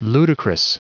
Prononciation du mot ludicrous en anglais (fichier audio)
Prononciation du mot : ludicrous